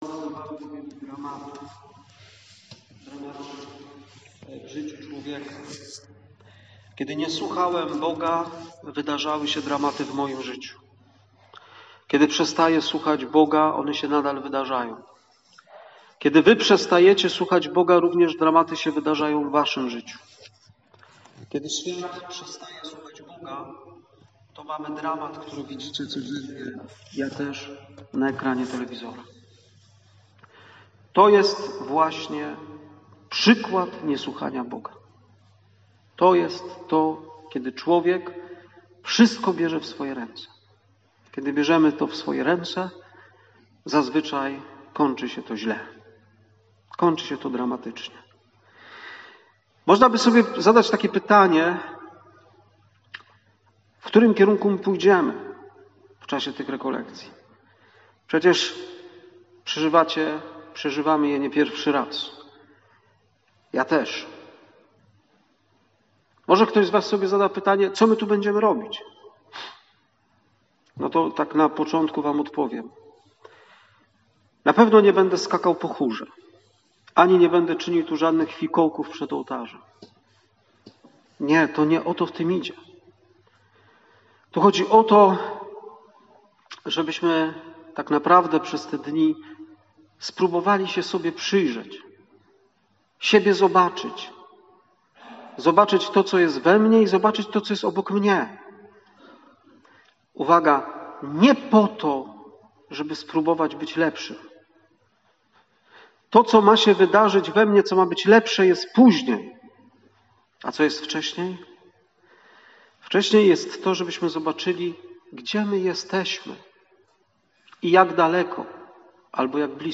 Rekolekcje Wielkopostne w Drugą Niedzielę Wielkiego Postu